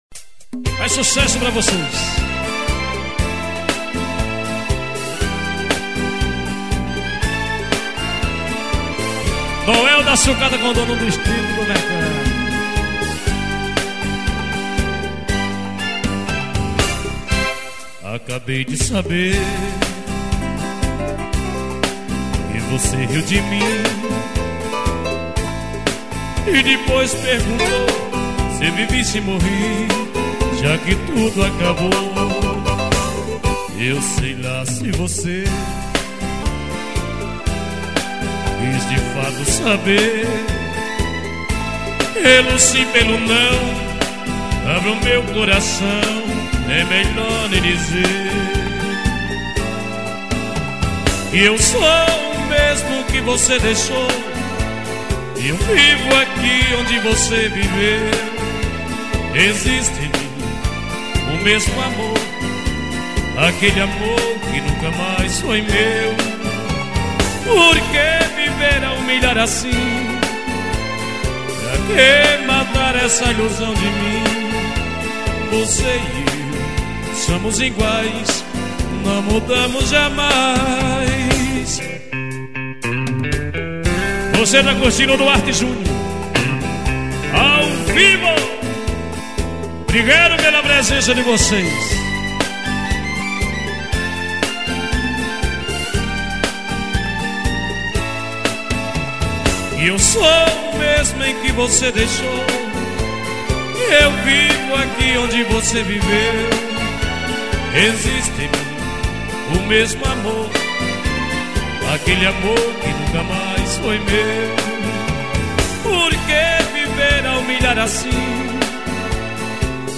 gravação de cd ao vivo.